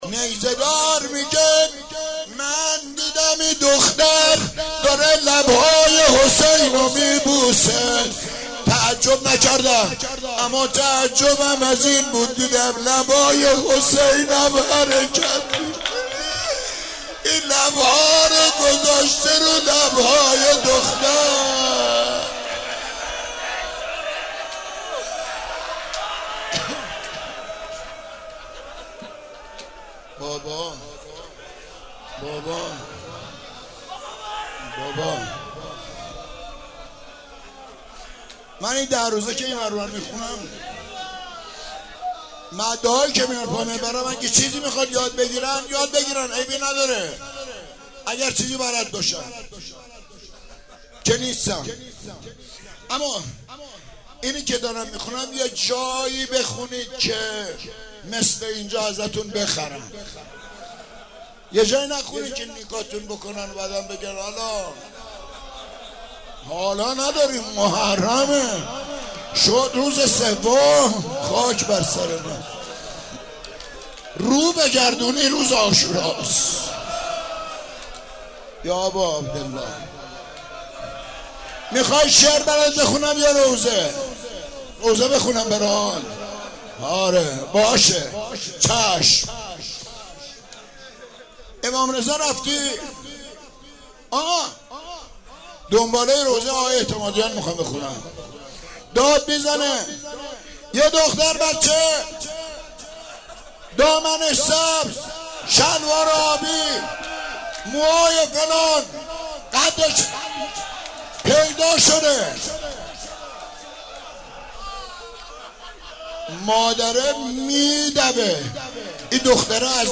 روضه حضرت رقیه